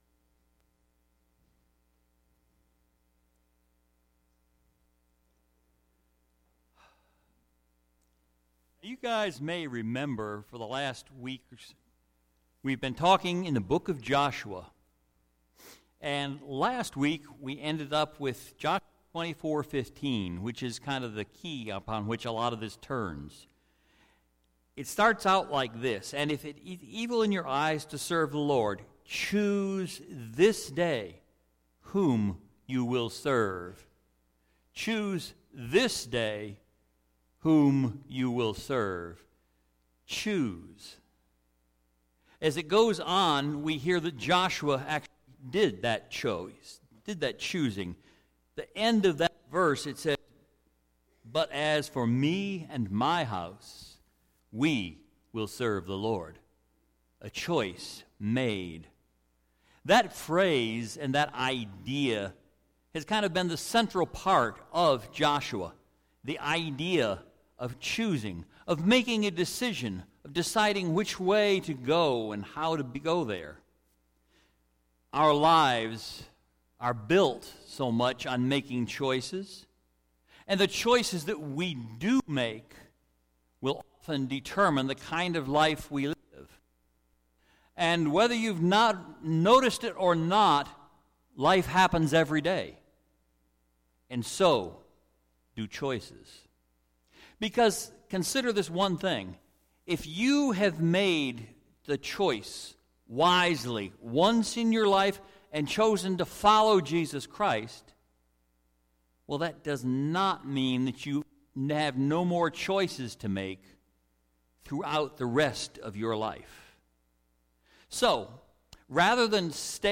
Sermons - Calvary Baptist Bel Air